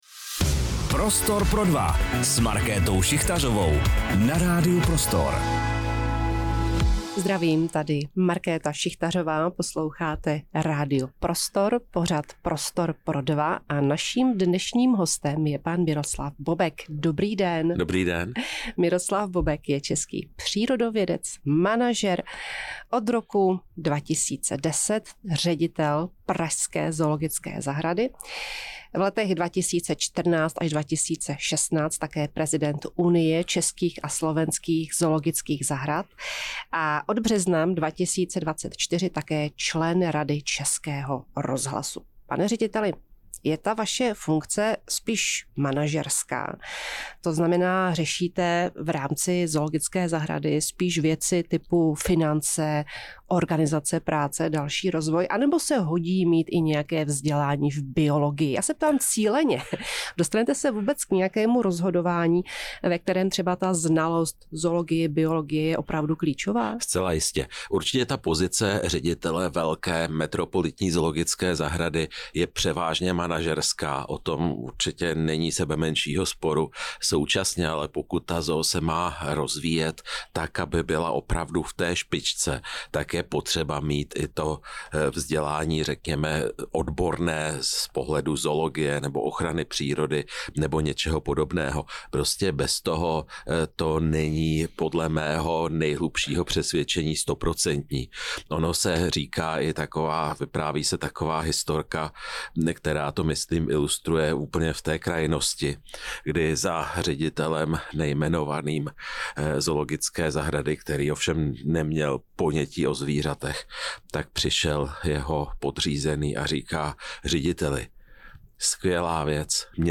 Rozhovor s Miroslavem Bobkem | Radio Prostor